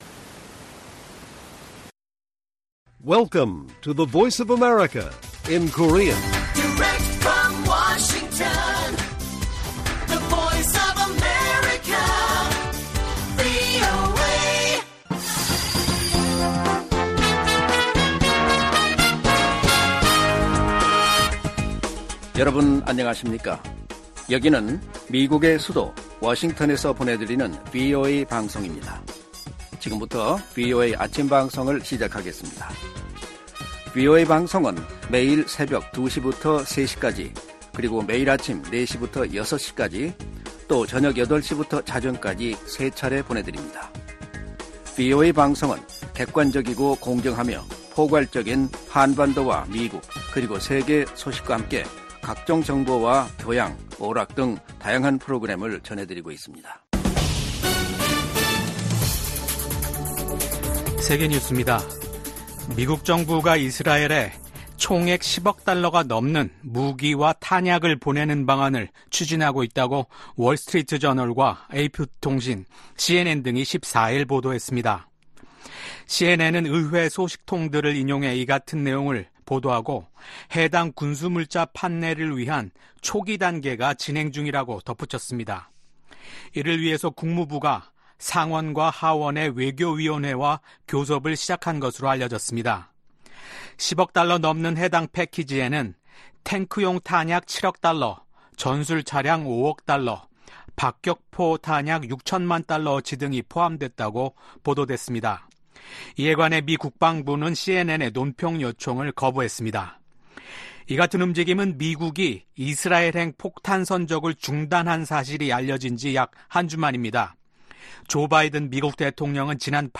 세계 뉴스와 함께 미국의 모든 것을 소개하는 '생방송 여기는 워싱턴입니다', 2024년 5월 16일 아침 방송입니다. '지구촌 오늘'에서는 조 바이든 미국 행정부가 10억 달러 이상 규모 무기의 이스라엘 제공 계획을 의회에 알렸다는 언론 보도 소식 전해드리고, '아메리카 나우'에서는 도널드 트럼프 전 대통령의 ‘성추문 입막음’ 의혹 재판의 핵심 증인인 마이클 코언 씨가 이틀째 증언에서 트럼프 전 대통령의 유죄 판결을 원한다고 밝힌 이야기 살펴보겠습니다.